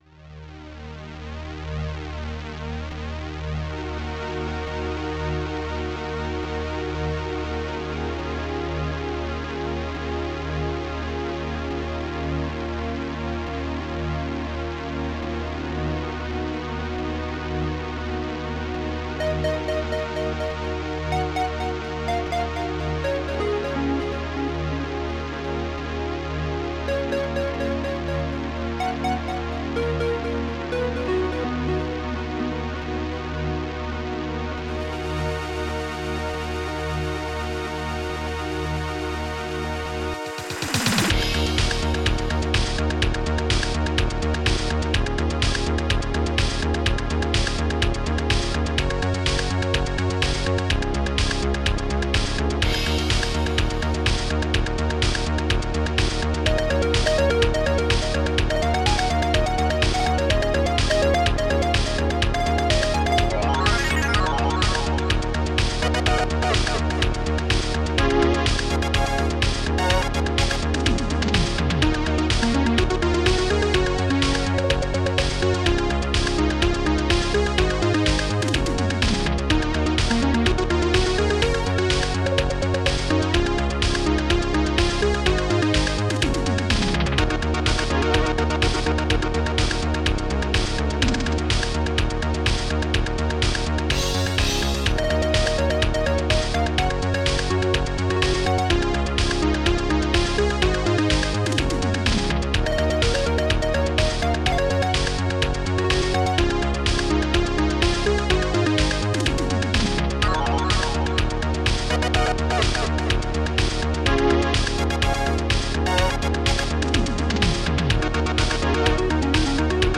ST-95:ffjunobass
ST-90:synthtom
ST-92:synthlead1
ST-92:horrorstring3
st-98:discobdrum.seq1
st-98:discohihat.seq4